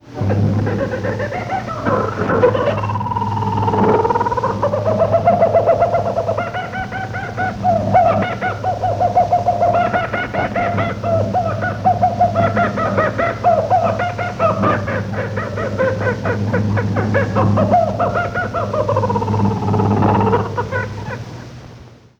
周波数：9760kHz
受信機：東芝 TRY-X2000 (RP-2000F)
ISのワライカワセミ（笑い翡翠）のフルバージョンのみです。
waraikawasemi_full_all.mp3